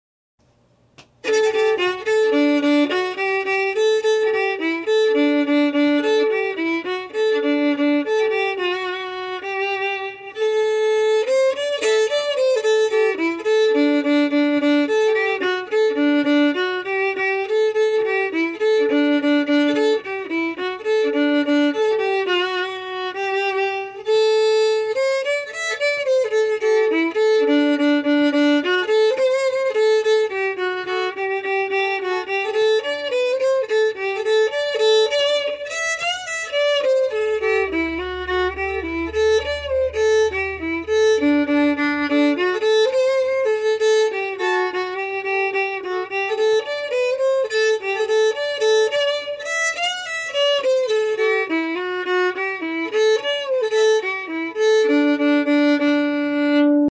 Key: Dmix
Form: Jig
Played slowly for learning
Source: Traditional
Region: Ireland